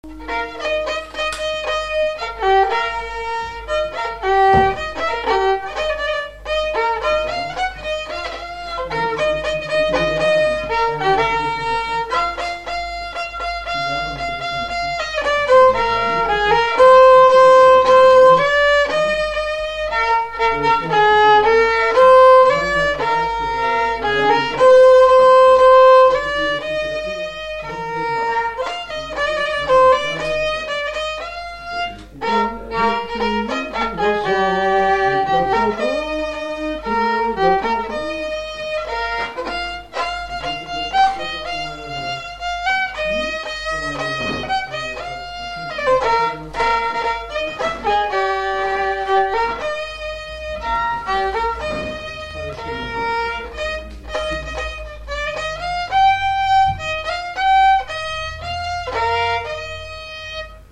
Résumé instrumental
Pièce musicale inédite